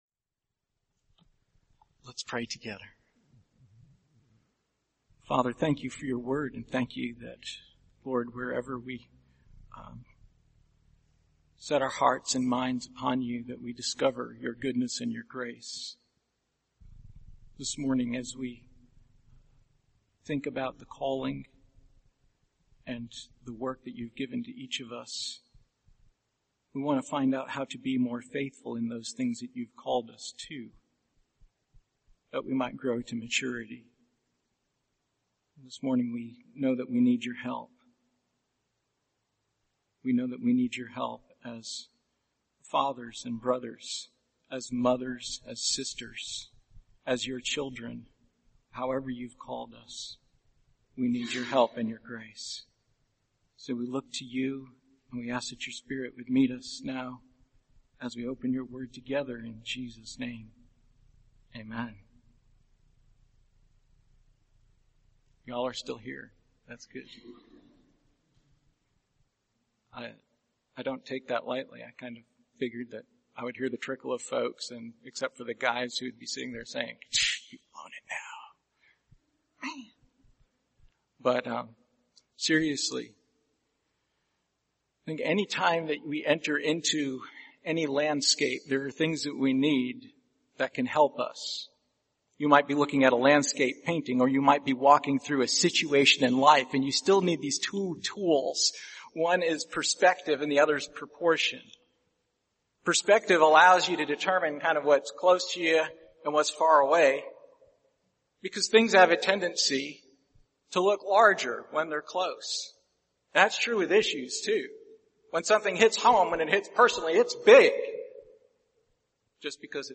Passage: 1 Corinthians 14:26-36 Service Type: Sunday Morning